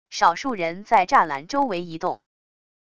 少数人在栅栏周围移动wav音频